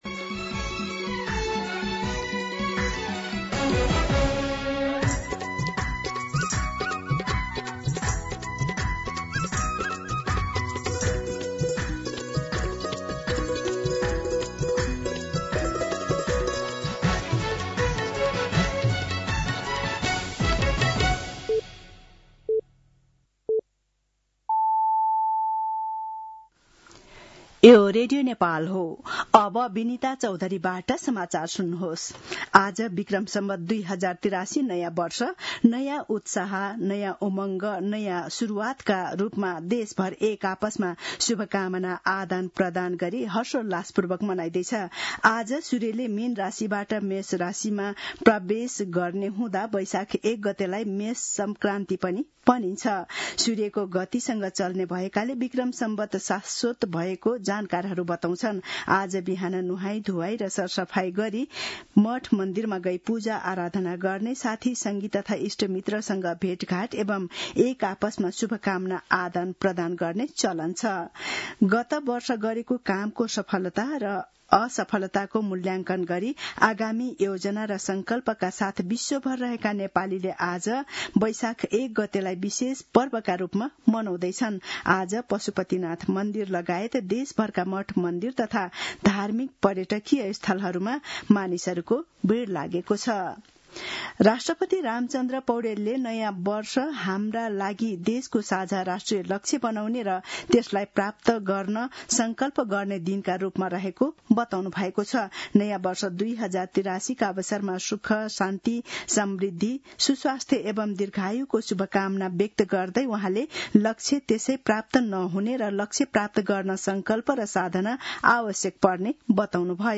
दिउँसो १ बजेको नेपाली समाचार : १ वैशाख , २०८३